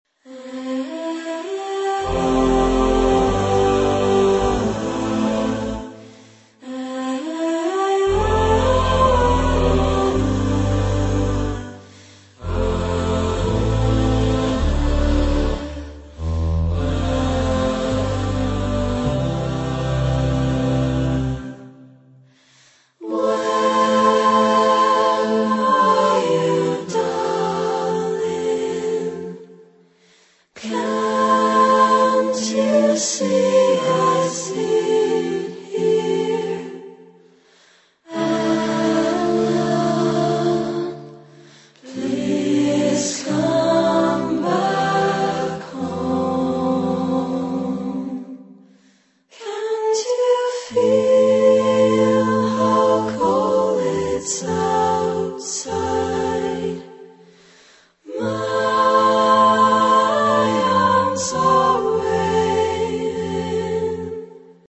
Genre-Style-Forme : Profane ; Jazz vocal ; Close Harmony
Type de choeur : SATB  (4 voix mixtes )
Tonalité : mineur ; accords de jazz